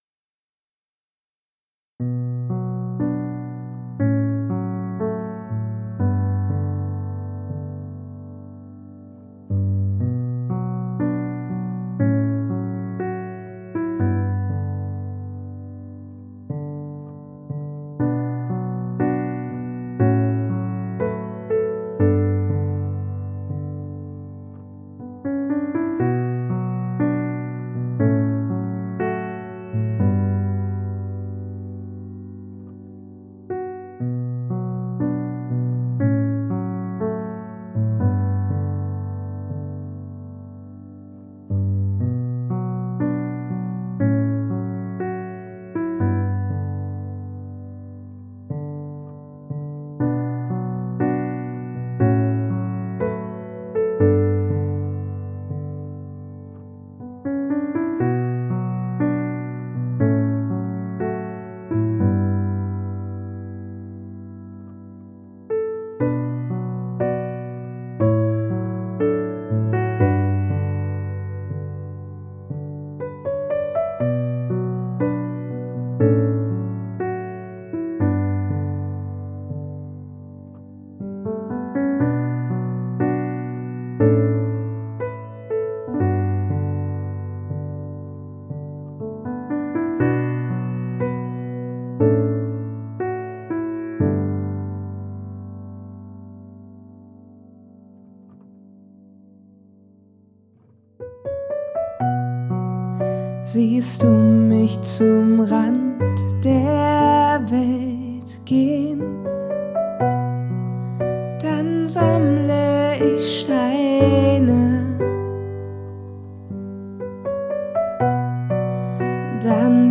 Das Klavier habe ich intern in der Software vom Sound verbreitert, um der Stimme in der Mitte ihren Spot zu geben. Der Raumeffekt in der Stimme ist recht subtil gehalten, entfaltet dennoch eine ahnungsvolle Breite.
Mir persönlich war es wichtig es so natürlich wie möglich klingen zu lassen